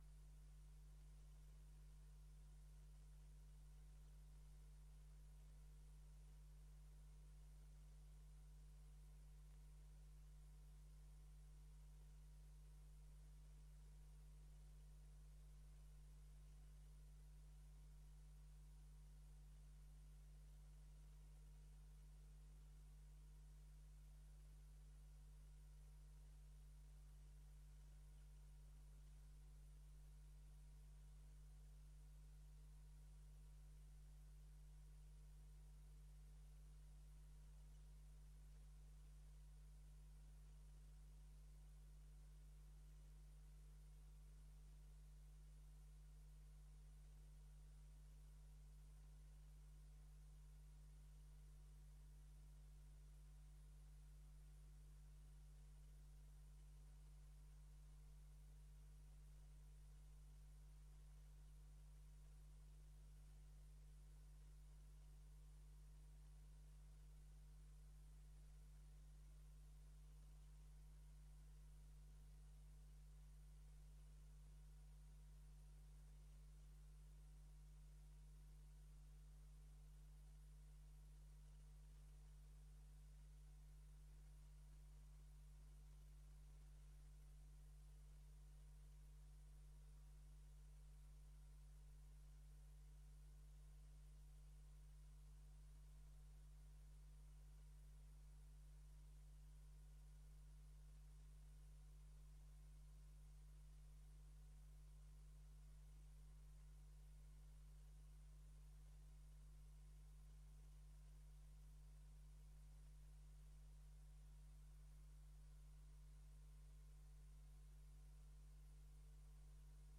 Gemeenteraad 24 juni 2024 21:00:00, Gemeente Dalfsen
Download de volledige audio van deze vergadering